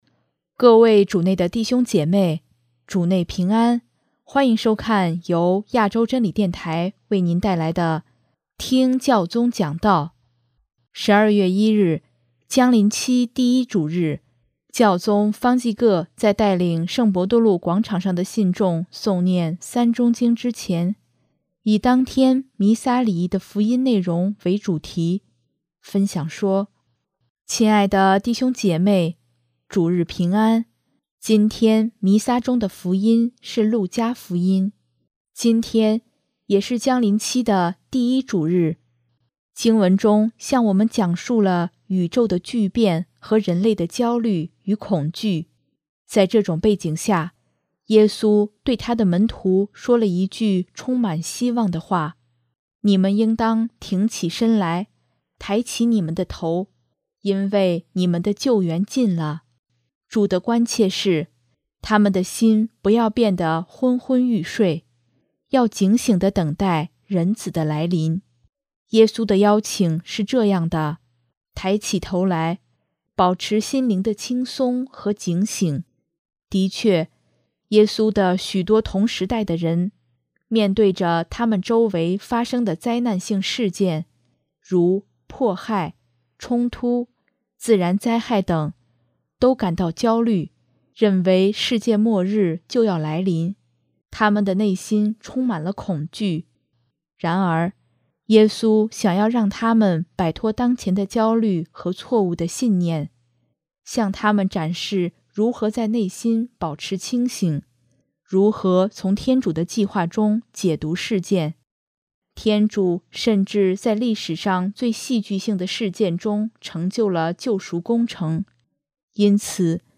12月1日，将临期第一主日。教宗方济各在带领圣伯多禄广场上的信众诵念《三钟经》之前，以当天弥撒礼仪的福音内容为主题，分享说：